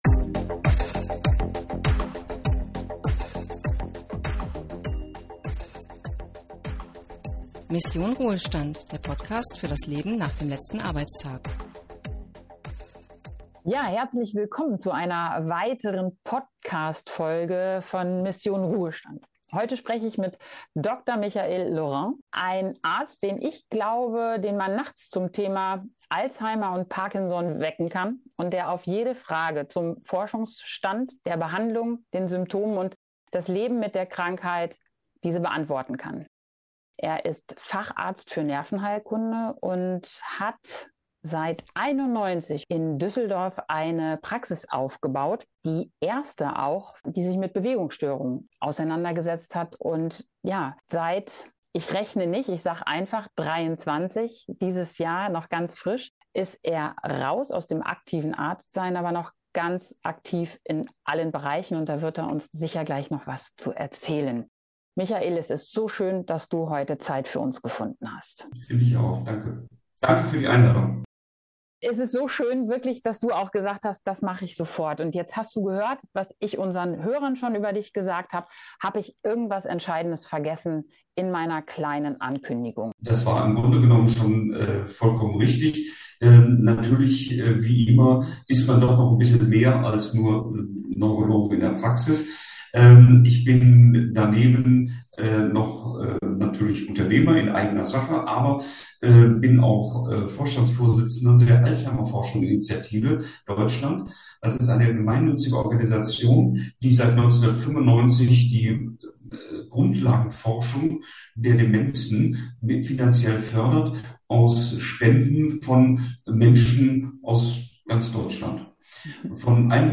In gut verständlicher Art und Weise klärt er auf und macht Mut.